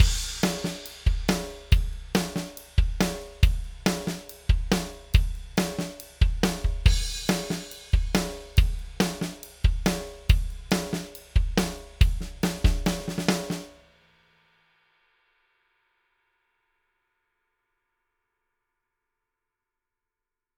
Drums | unbearbeitet
presonus_ctc-1_pro_console_shaper_test__drums_1__dry.mp3